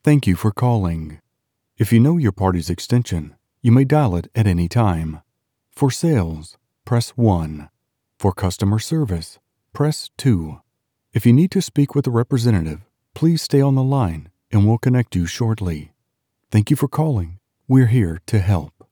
All our voice actors are premium seasoned professionals.
On Hold, Professional Voicemail, Phone Greetings & Interactive Voice Overs
Adult (30-50) | Older Sound (50+)